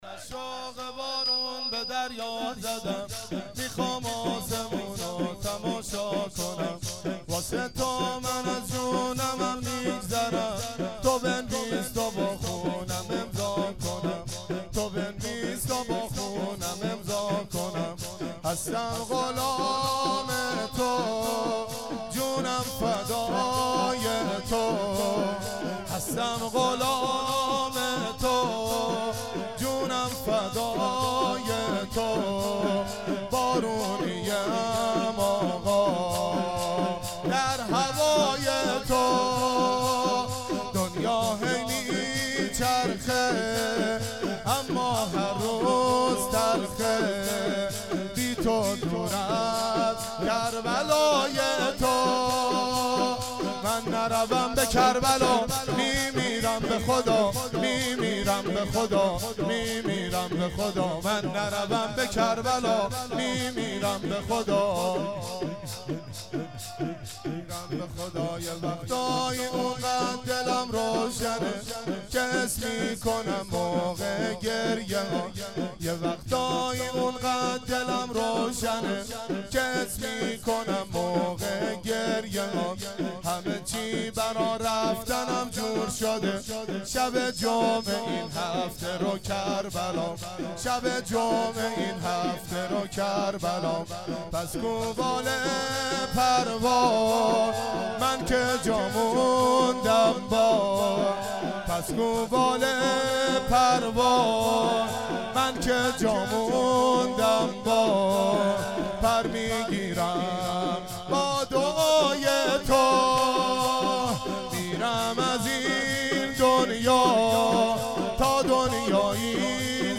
شور،من از شوق بارون به دریا زدم